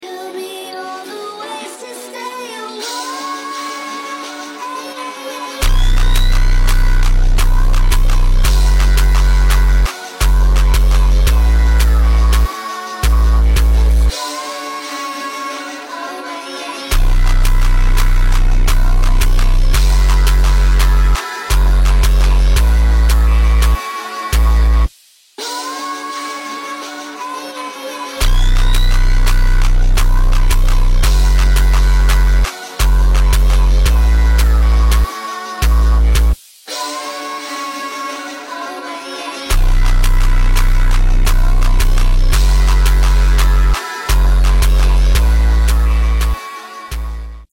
Sounding mint raw sx85 sound